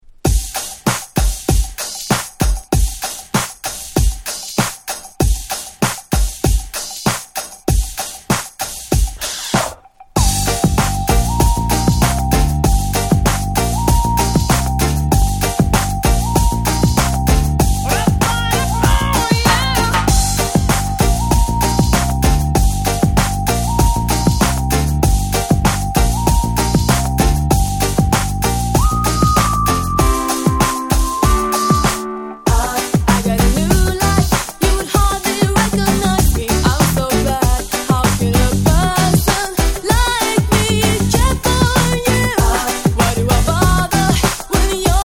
【Media】Vinyl 12'' Single
この曲のヒット後、この曲のポコポコした何とも言えないBeatを模倣したDance PopやR&Bが急増！！